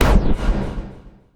select.wav